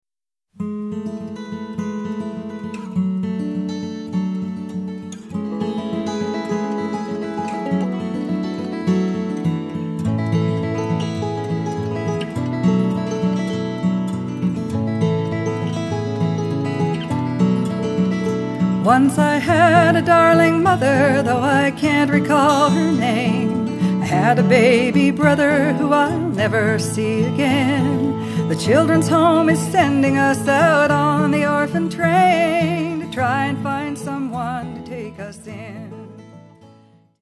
Guitar and harmonies